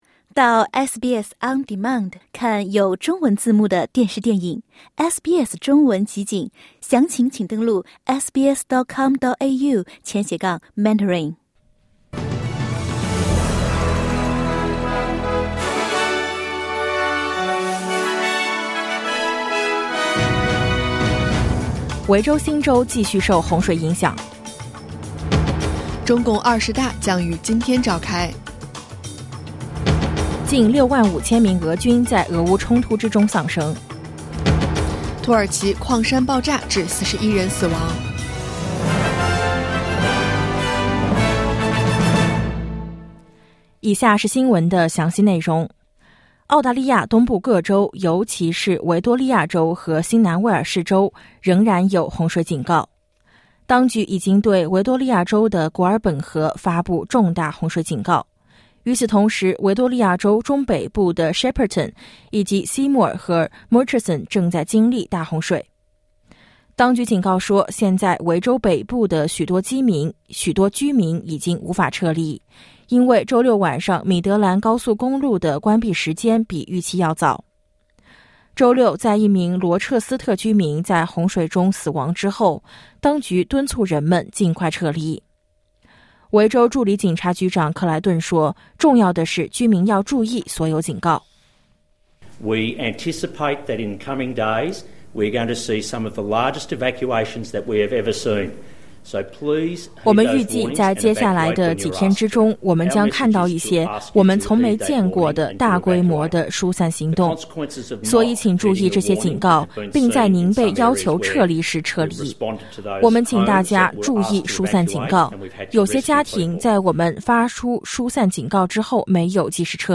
SBS早新闻（2022年10月16日）